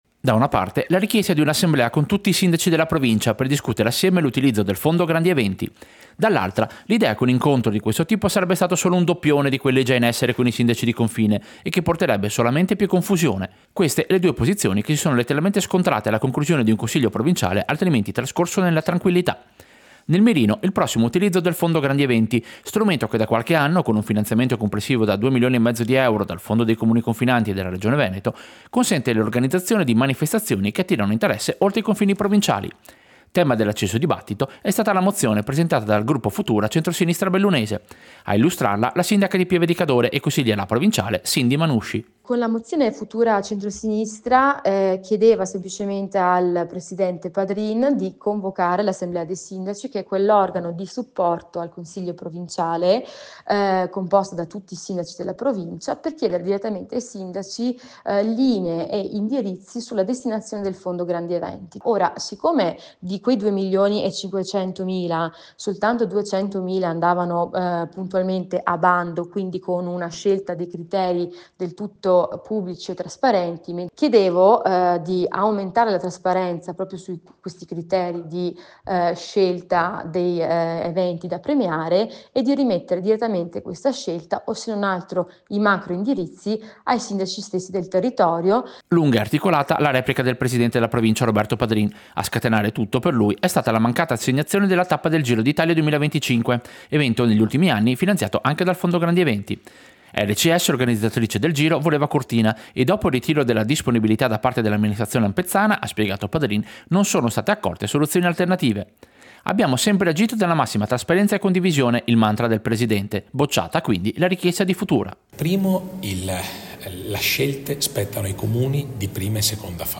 Servizio-Consiglio-provinciale-Mozione-grandi-eventi.mp3